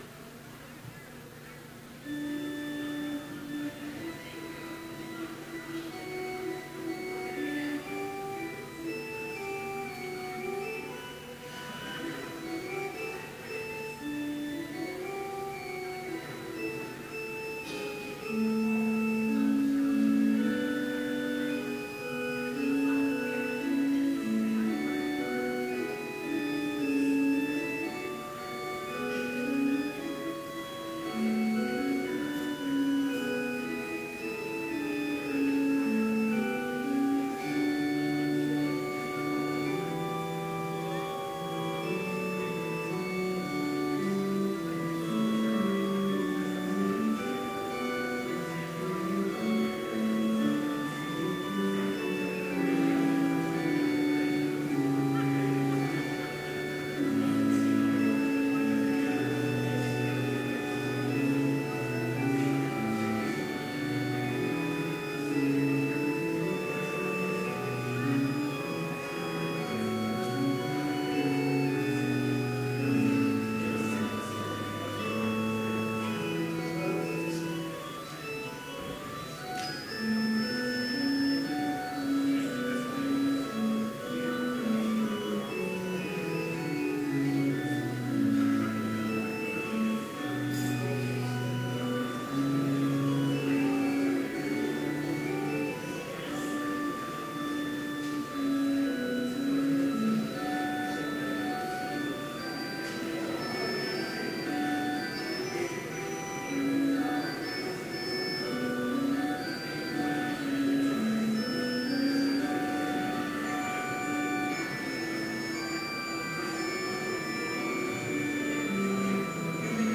Chapel worship service held on October 18, 2017, BLC Trinity Chapel, Mankato, Minnesota, (video and audio available)
Complete service audio for Chapel - October 18, 2017